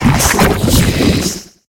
Cri de Krakos dans Pokémon HOME.